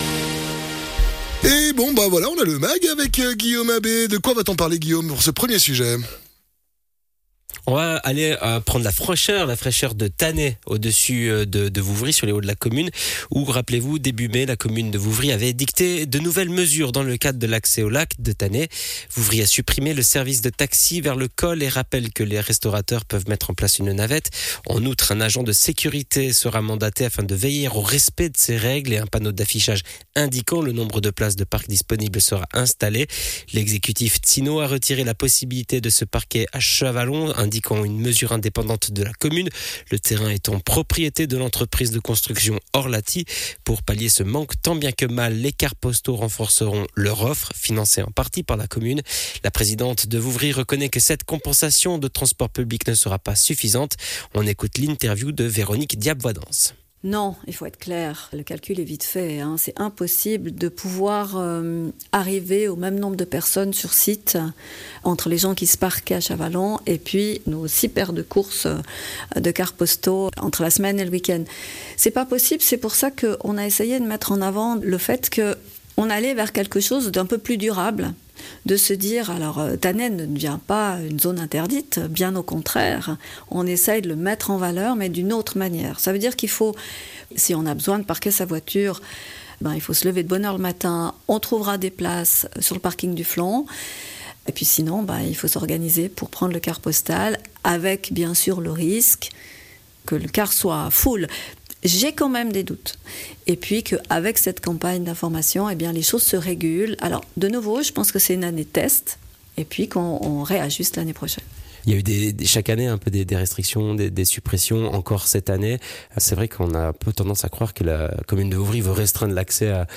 Grande Interview: Stéphane Rossini, anc. directeur de l'OFAS